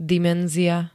dimenzia [d-] -ie pl. G -ií D -iám L -iách ž.
Zvukové nahrávky niektorých slov